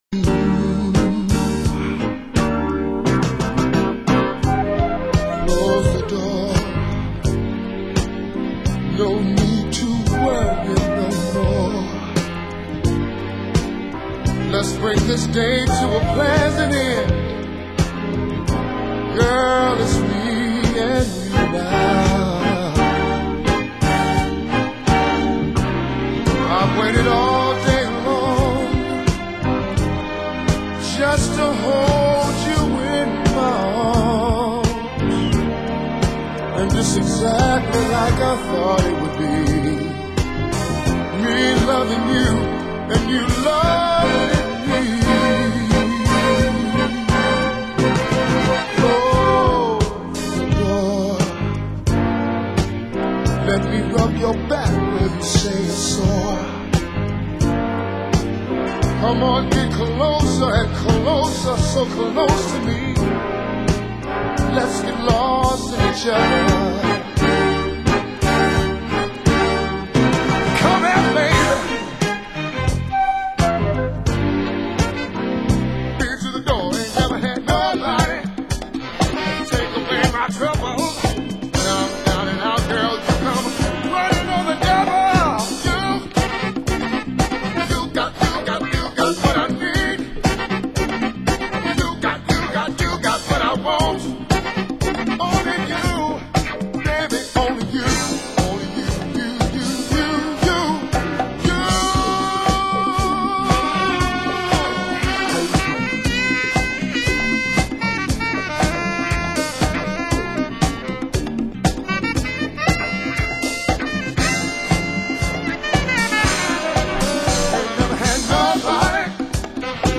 Genre Disco